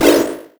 confirm.wav